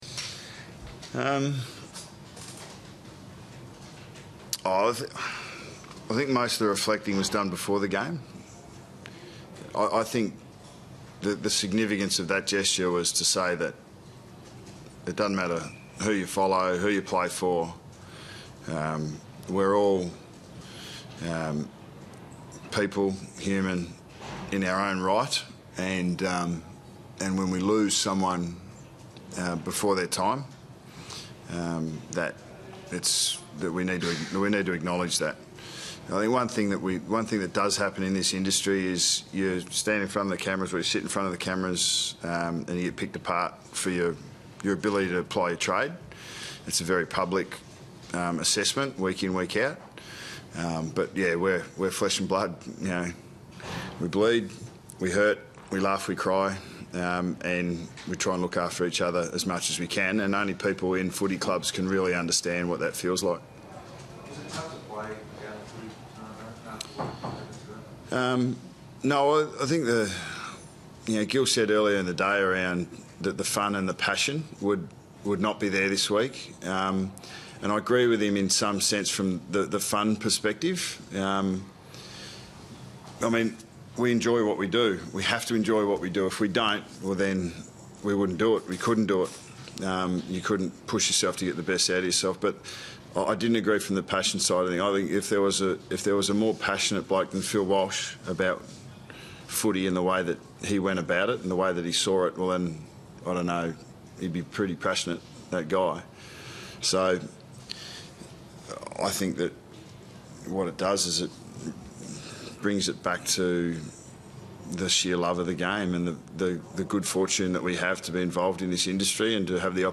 Listen to coach Nathan Buckley reflect on Friday night's match and the passing of Phil Walsh on Friday 3 July 2015.